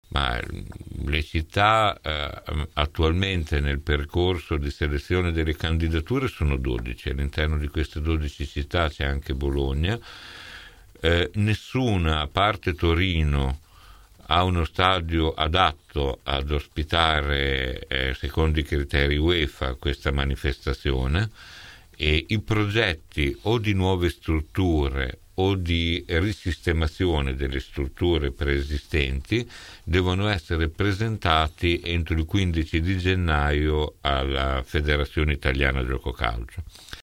17 nov. – Questa mattina ai nostri microfoni l’assessore all’urbanistica, ambiente e sport del comune di Bologna Maurizio Degli Esposti ha risposto su una serie di punti a cominciare dalla questione dei “23 saggi” chiamati a “riprogettare Bologna” che, afferma l’assessore, non hanno alcun potere decisionale